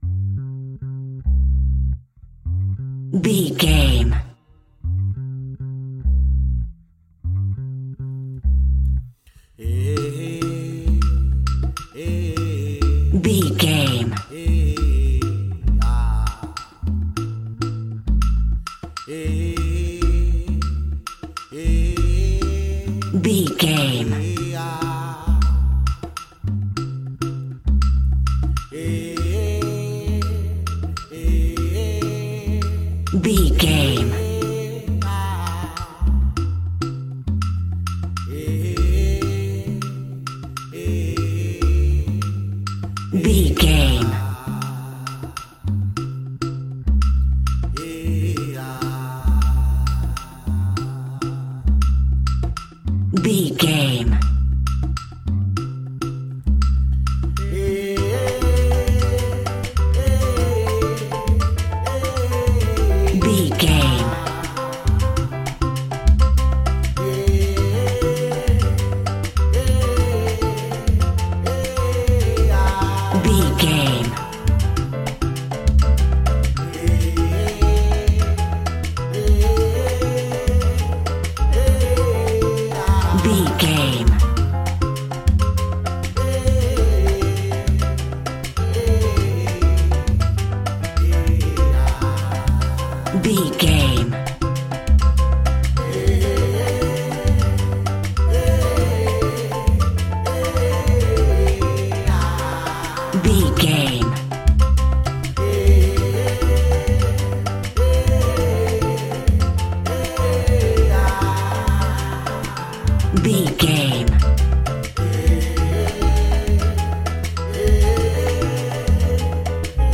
Ionian/Major
maracas
percussion spanish guitar